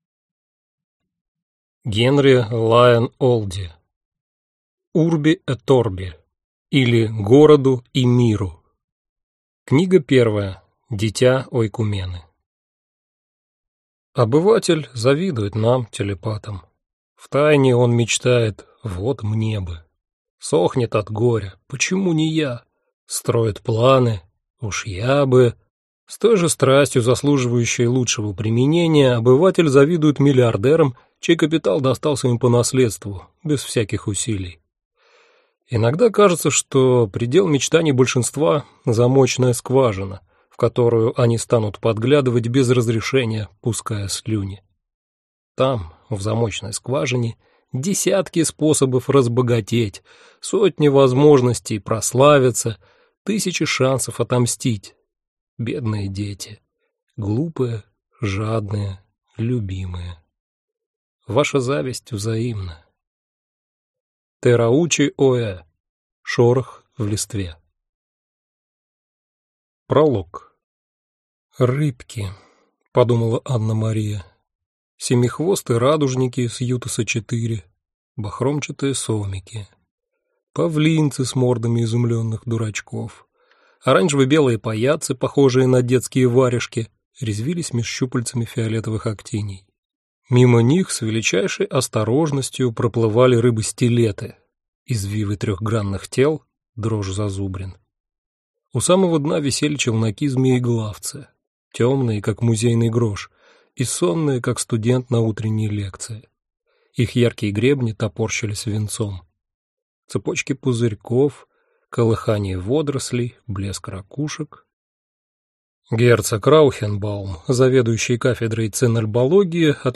Аудиокнига Дитя Ойкумены | Библиотека аудиокниг